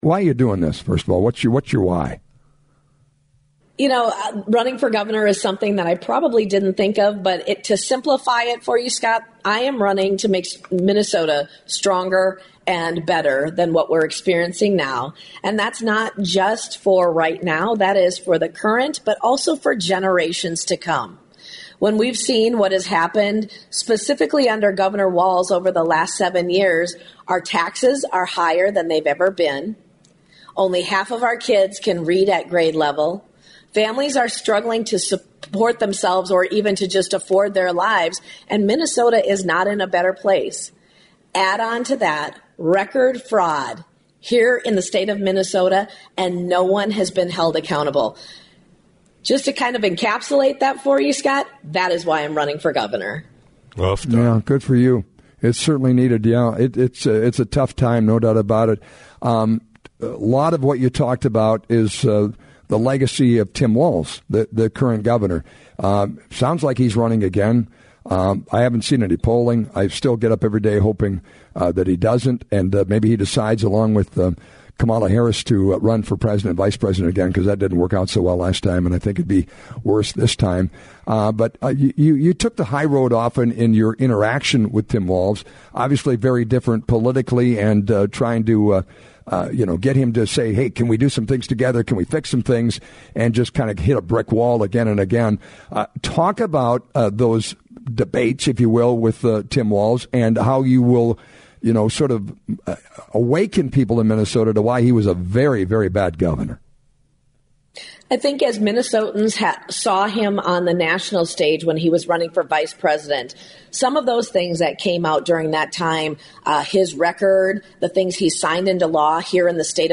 LISTEN: Minnesota Speaker of the House Lisa Demuth’s interview